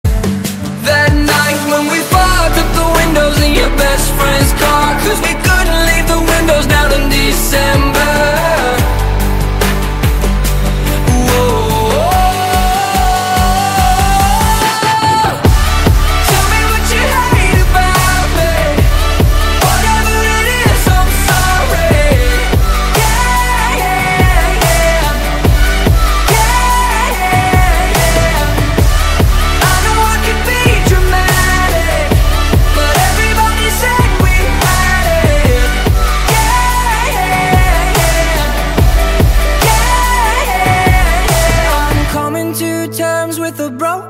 Dance - Electronics